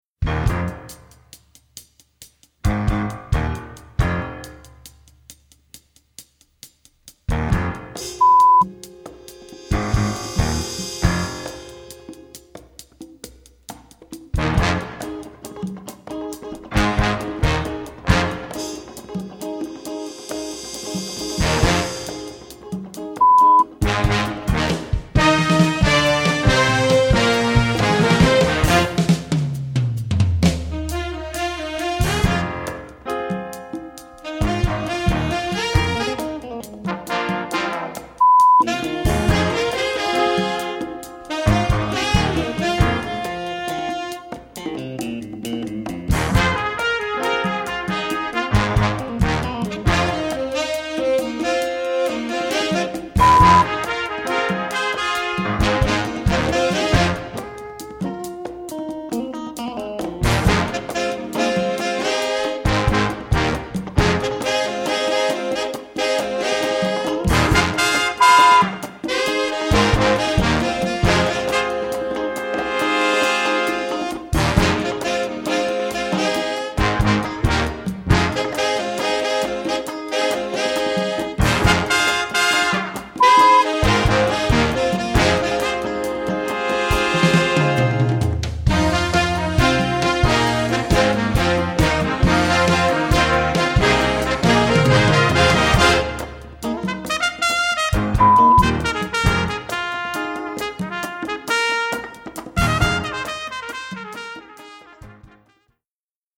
Répertoire pour Jazz band